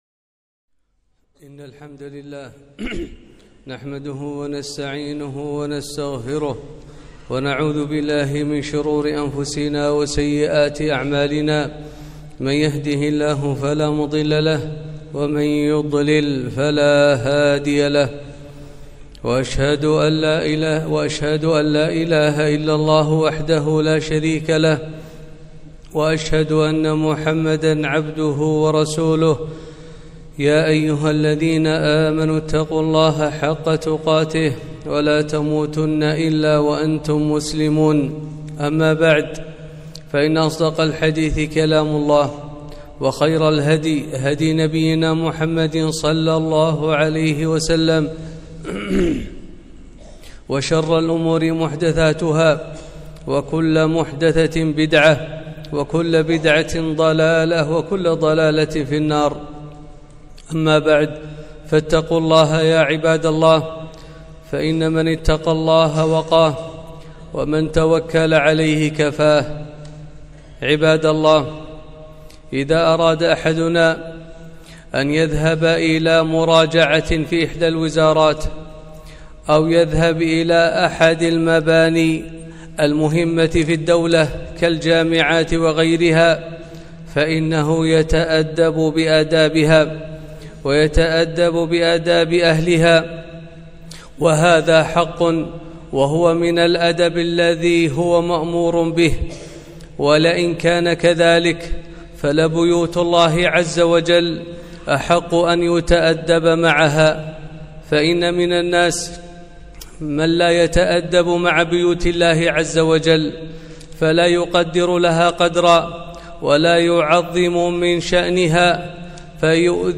خطبة - آداب المساجد